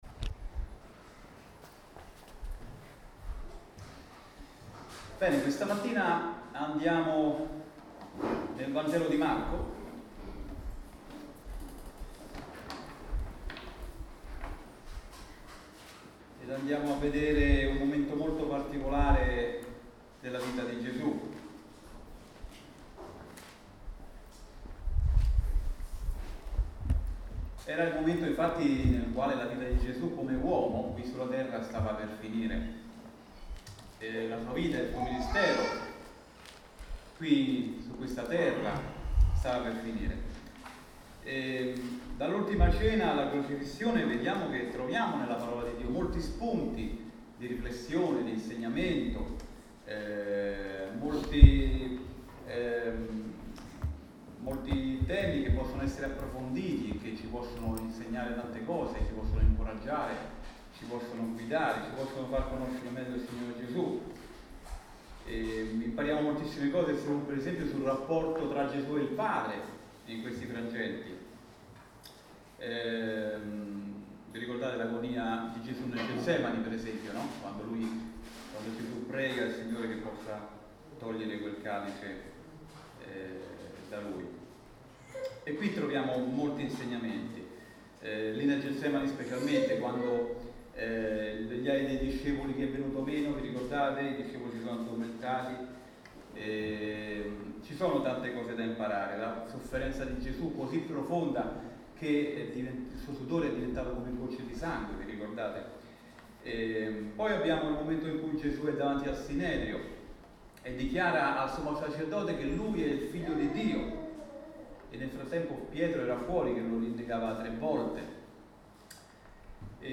Insegnamenti dal passo di Marco 14:42-52